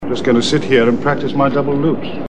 The Fourth Doctor is pretty fun when he sulks